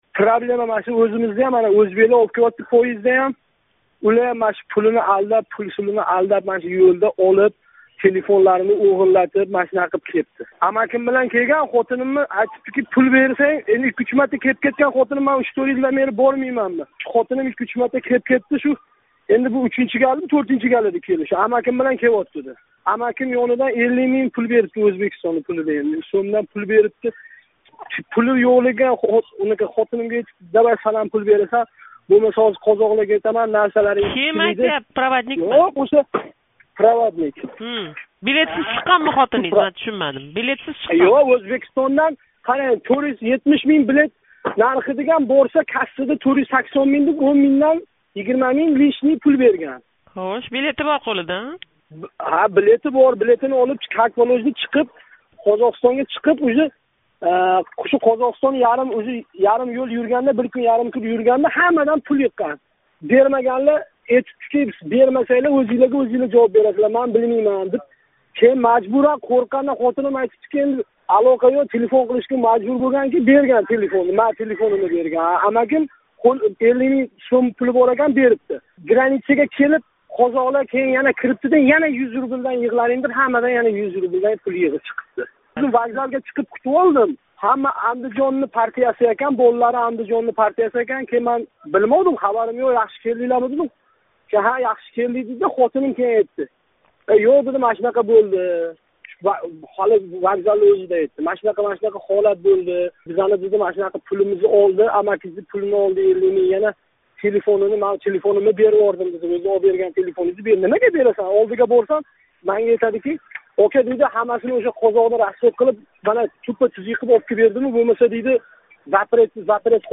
Россиядаги муҳожир билан суҳбатни қуйида тинглашингиз мумкин:
Проводниклар ҳақида суҳбат